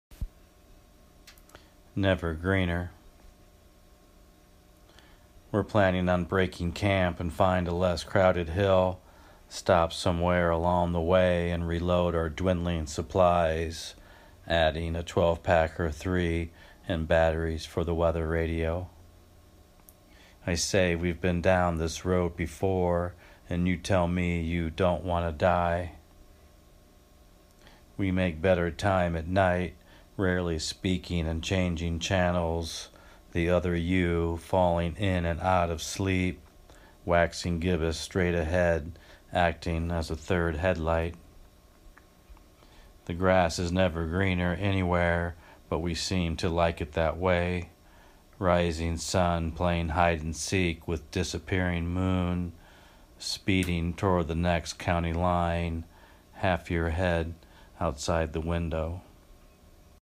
Excellent poem and reading.